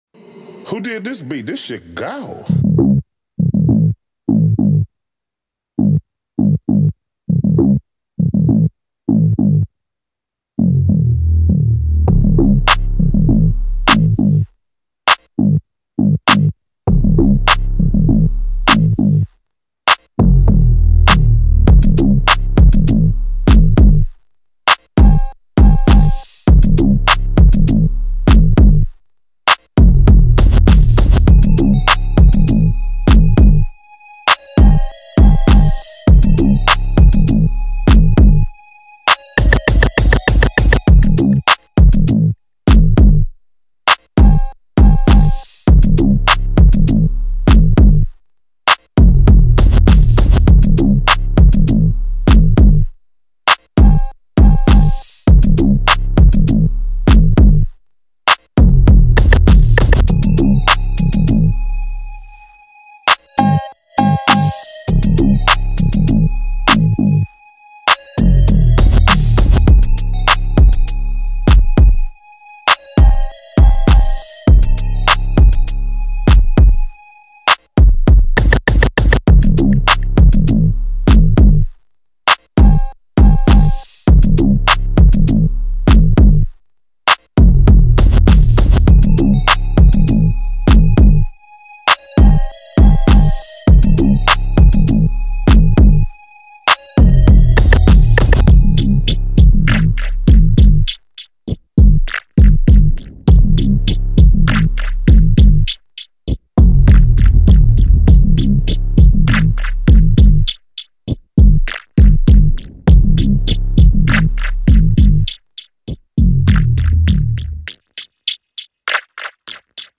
official instrumental
West Coast Instrumentals